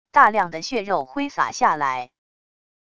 大量的血肉挥洒下来wav音频